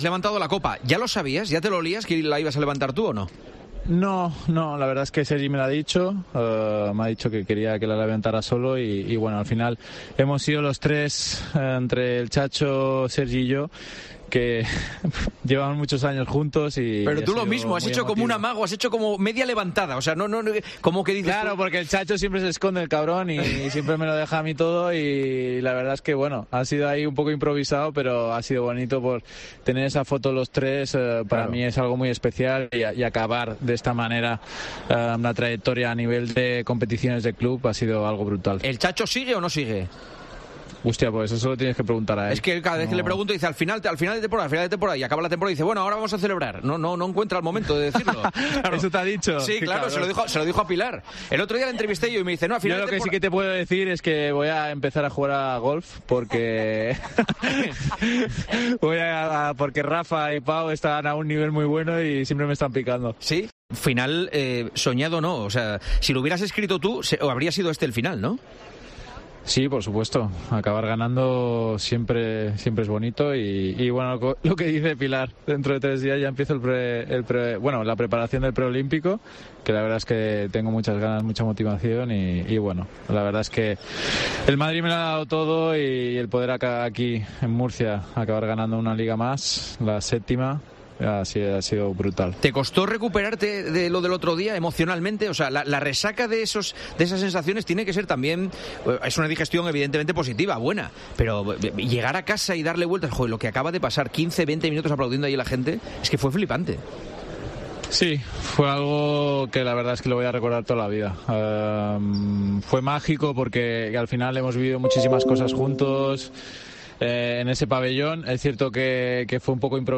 El mallorquín se muestra feliz en El Partidazo al cerrar su carrera en el Real Madrid con la liga.